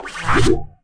Block Trigger Disappear Sound Effect
Download a high-quality block trigger disappear sound effect.
block-trigger-disappear.mp3